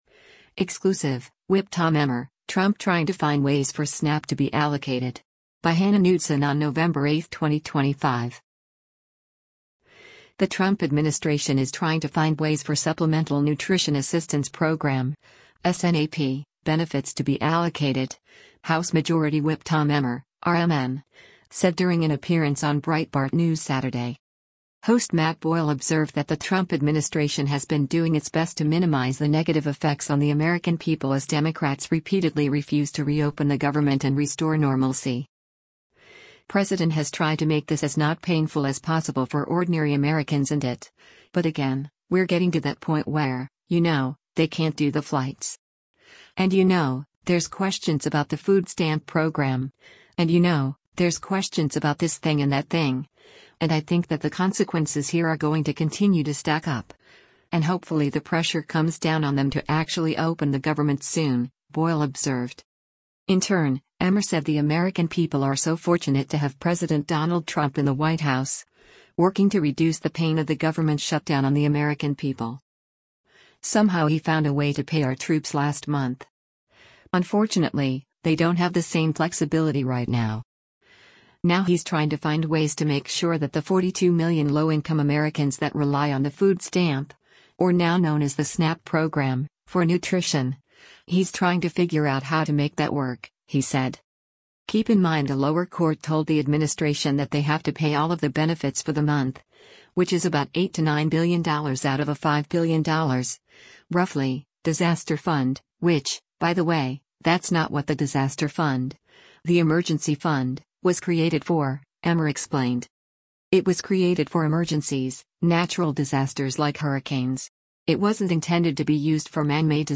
The Trump administration is “trying to find ways” for Supplemental Nutrition Assistance Program (SNAP) benefits to be allocated, House Majority Whip Tom Emmer (R-MN) said during an appearance on Breitbart News Saturday.
Breitbart News Saturday airs on SiriusXM Patriot 125 from 10:00 a.m. to 1:00 p.m. Eastern.